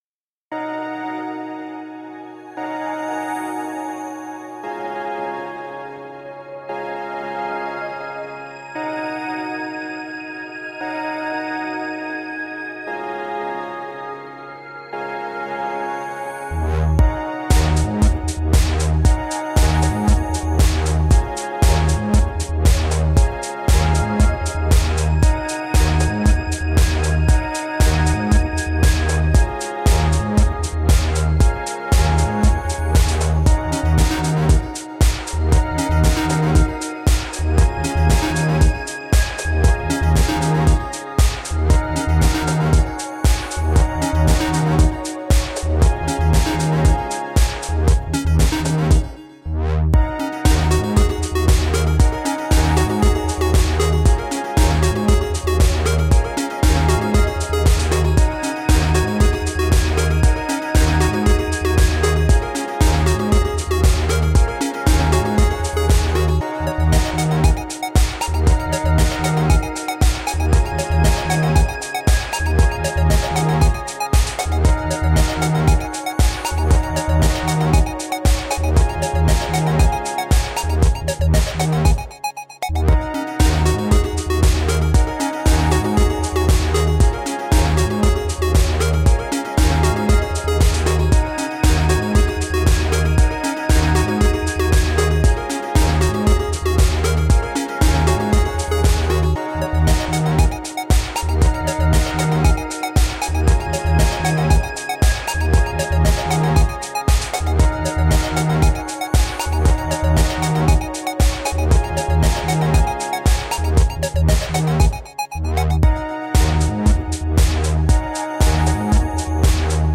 The most uplifting electronic music.
The most uplifting melodic dreamy cosmic dance music.